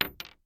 Bullet Shell Sounds
pistol_wood_5.ogg